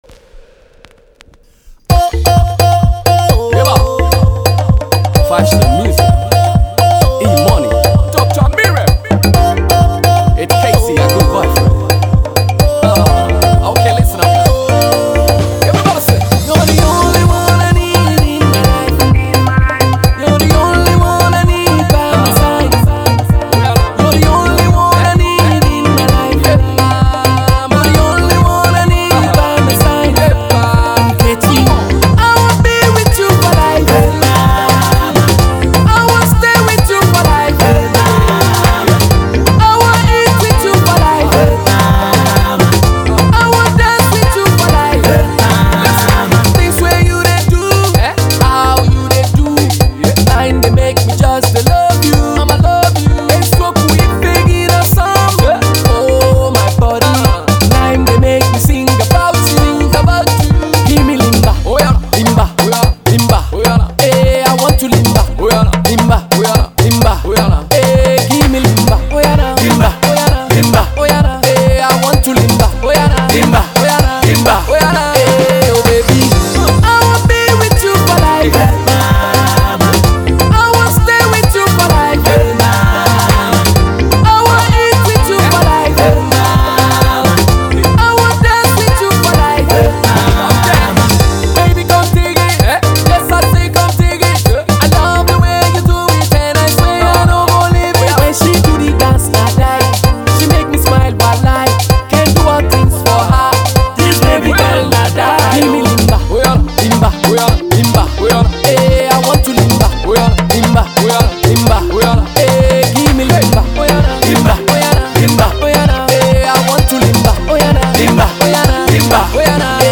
A Dance/Pop Song